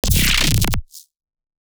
OTT Artifact 2.wav